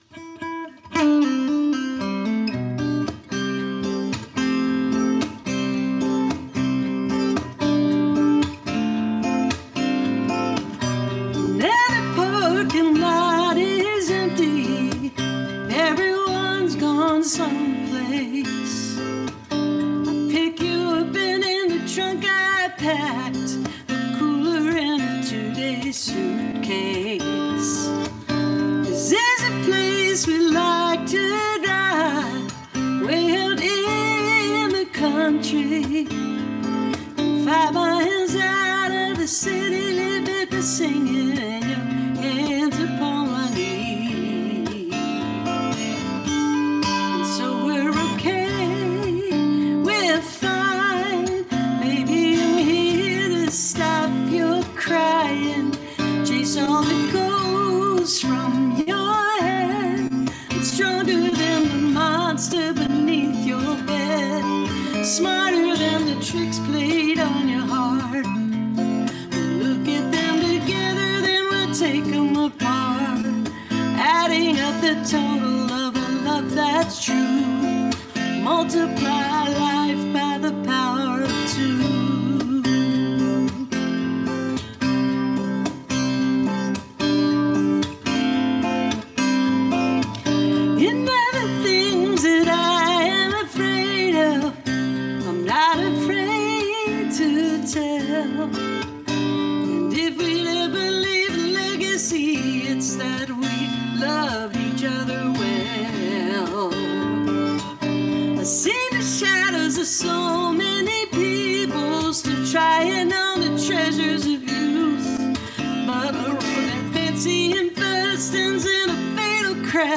(captured from webcast)